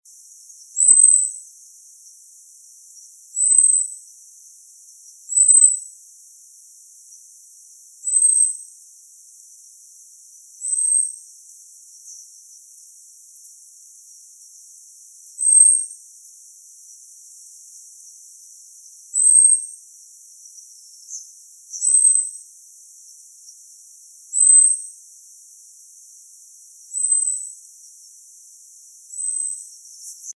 Mito (Aegithalos caudatus)
Nombre en inglés: Long-tailed Tit
Fase de la vida: Adulto
Localidad o área protegida: Botanic Garden de Cambridge
Condición: Silvestre
Certeza: Fotografiada, Vocalización Grabada